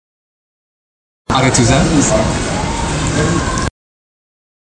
prononciation Aretusa